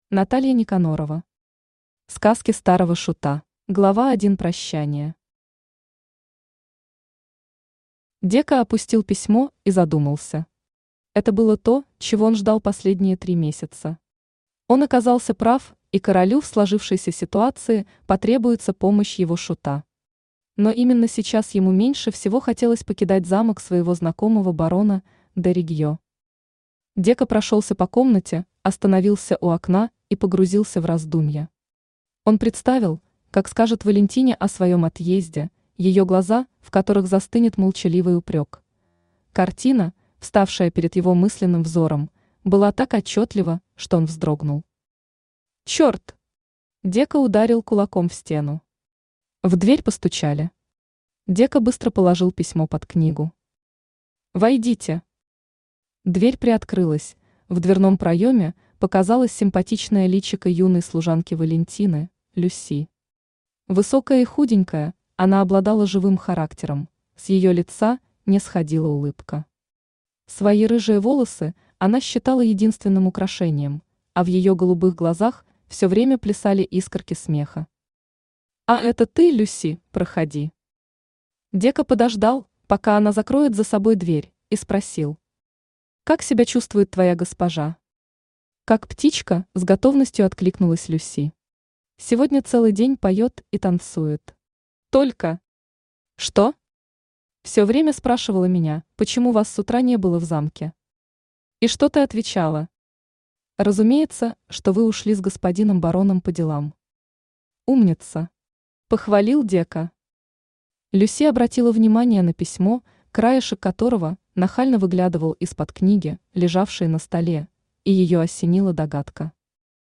Аудиокнига Сказки старого шута | Библиотека аудиокниг
Aудиокнига Сказки старого шута Автор Наталья Владимировна Никанорова Читает аудиокнигу Авточтец ЛитРес.